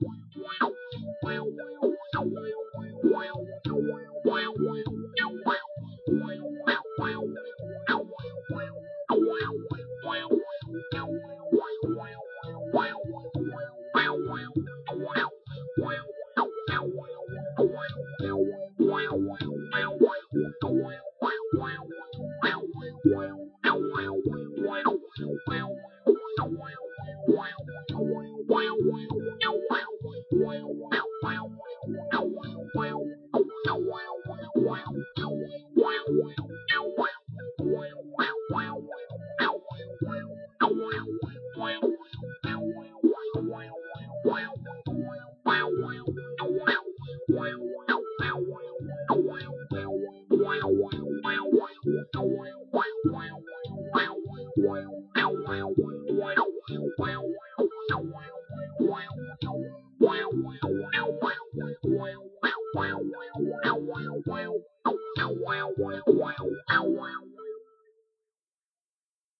Happy sounding track ment to brighten your day. Made with Audacity and my keyboard.